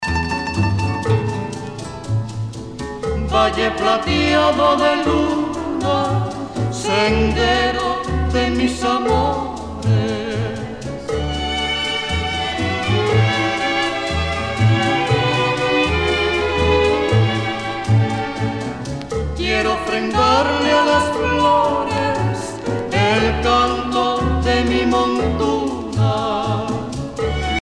danse : guaracha
Pièce musicale éditée